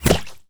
pgs/Assets/Audio/Guns_Weapons/Bullets/bullet_impact_mud_03.wav
bullet_impact_mud_03.wav